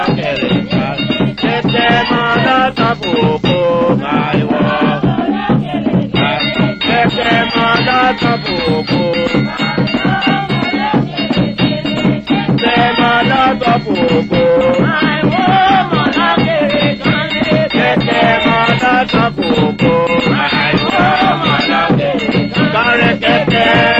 These tapes relate to the work done by anthropologist Melville Herskovits in Toco during the late l930s, as fieldwork for the publication by Melville and Frances Herskovits: Trinidad Village, (New York: Knopf, 1940).
Oral Tradition, Songs, Melville Herskovits, Music, Folklore, Trinidad and Tobago